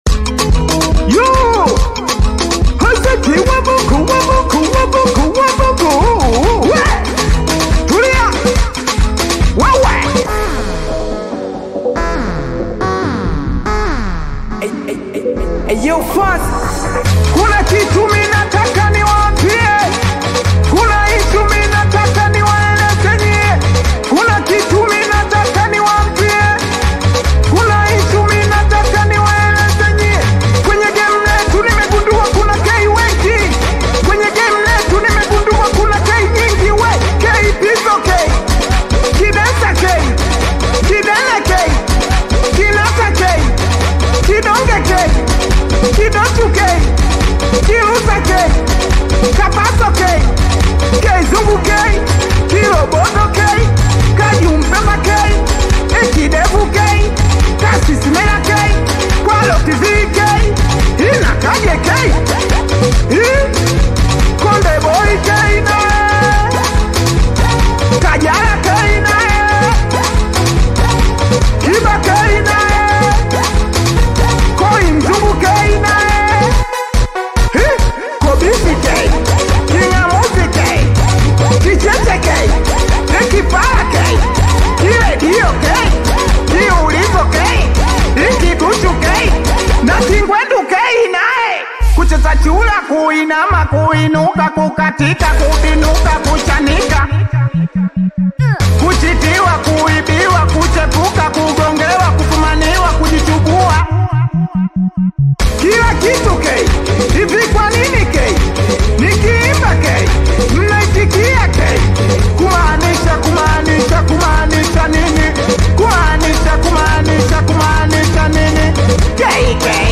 SINGELI MUSIC